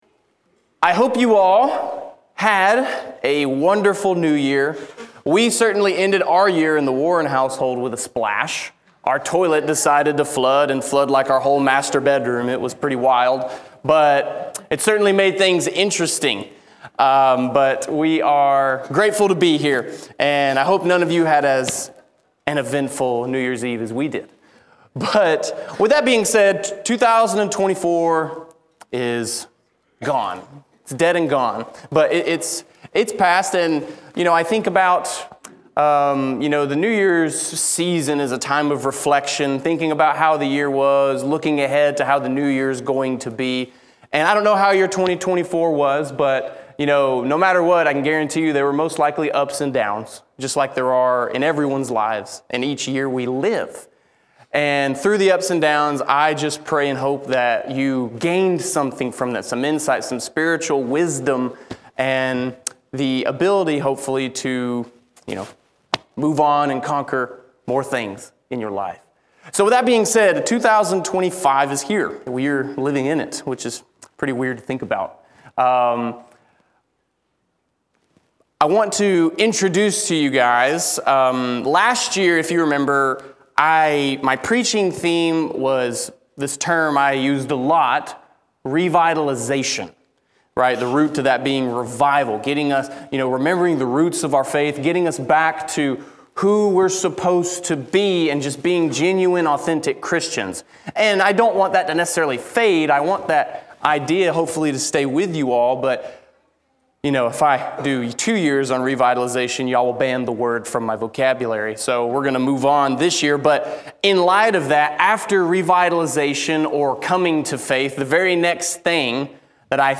Audio Sermons - Canyon View Church of Christ
Sunday Morning - 02/09/25